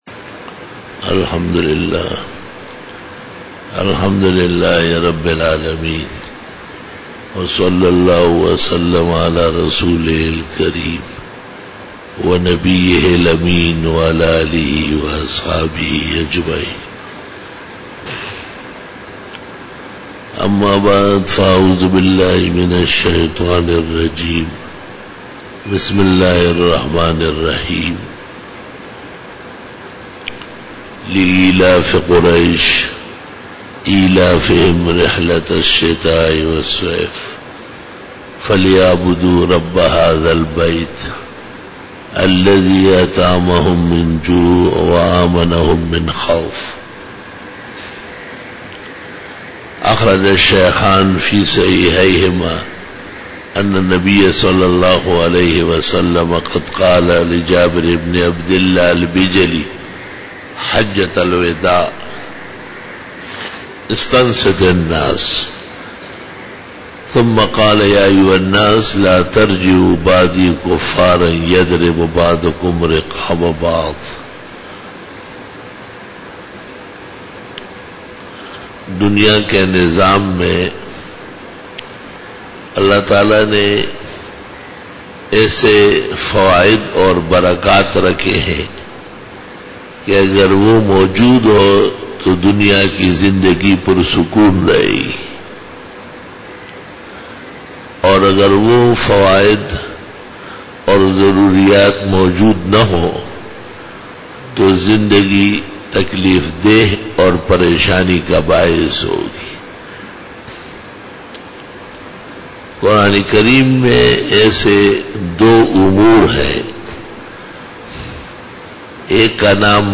18_BAYAN E JUMA TUL MUBARAK 02-MAY-2014
Khitab-e-Jummah 2014